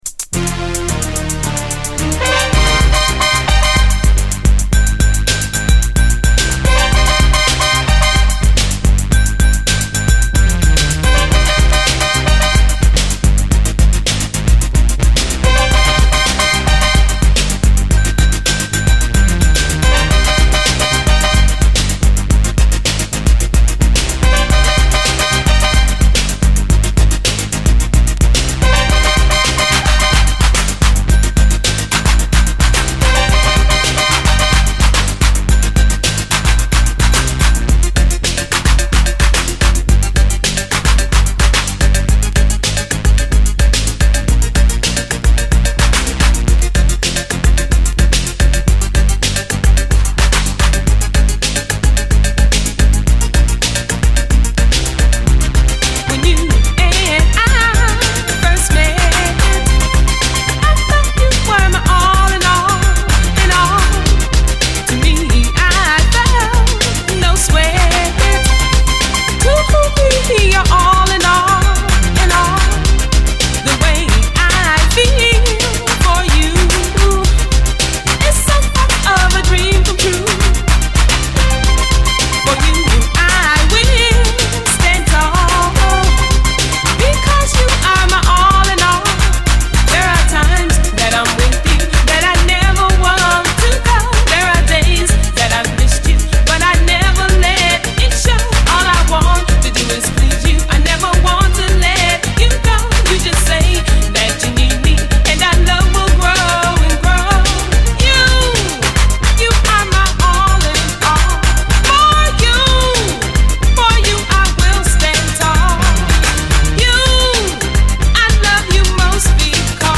熱帯雨林SEの静かなイントロ入り